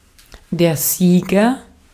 Ääntäminen
Vaihtoehtoiset kirjoitusmuodot champian Synonyymit friend lulu Ääntäminen US UK Tuntematon aksentti: IPA : /ˈtʃæmpiən/ Haettu sana löytyi näillä lähdekielillä: englanti Käännös Konteksti Ääninäyte Substantiivit 1.